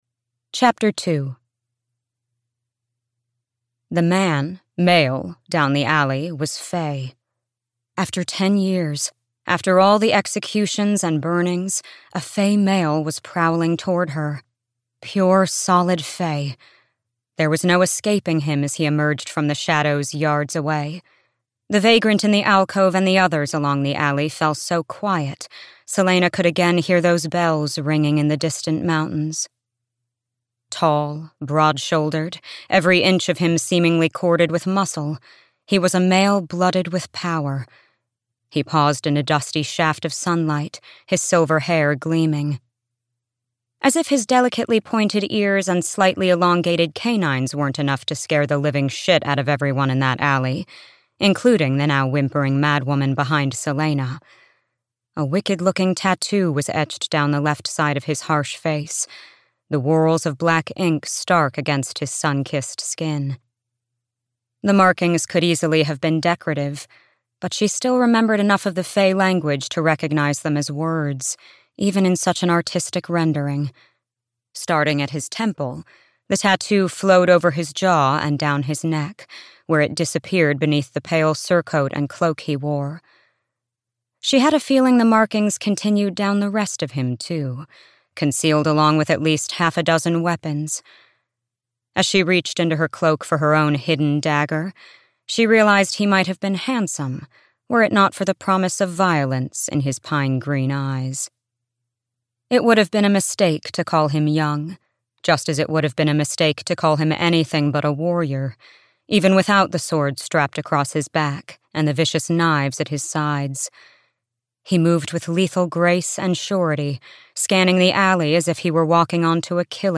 Heir of Fire (EN) audiokniha
Ukázka z knihy
heir-of-fire-en-audiokniha